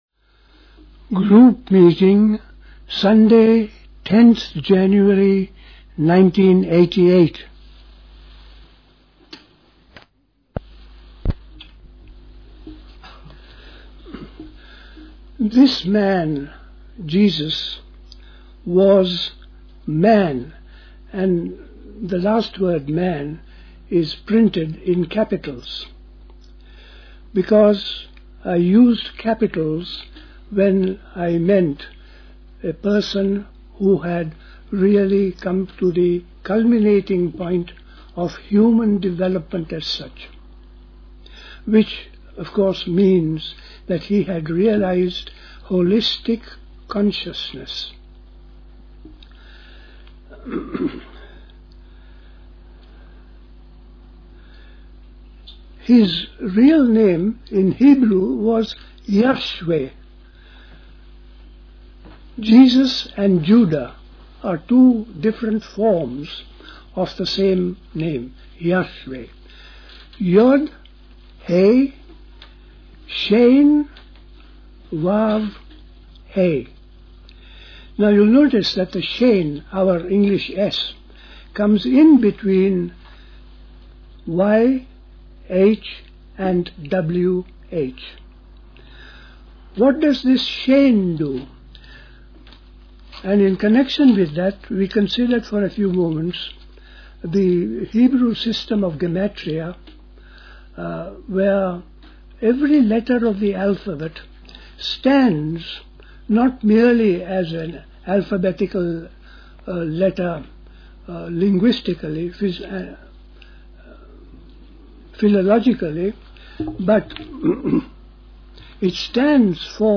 A talk
Dilkusha, Forest Hill, London